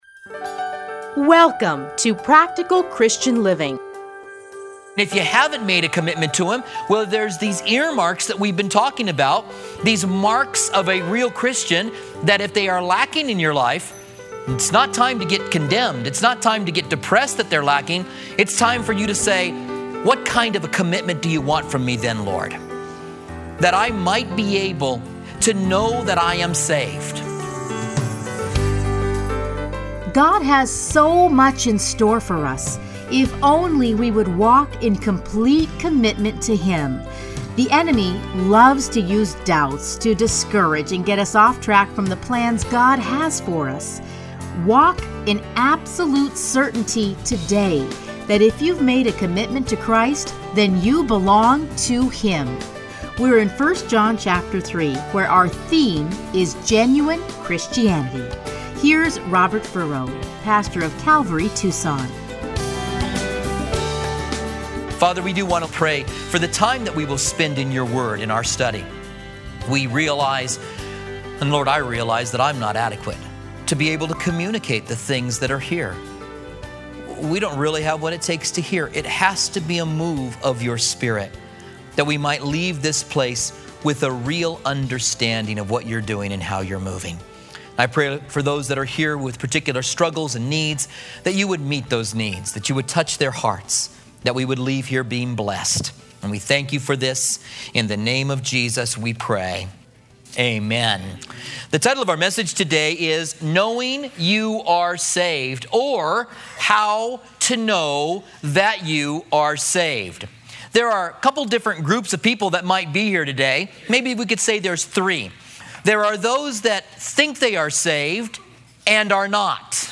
Listen here to his studies in the book of 1 John.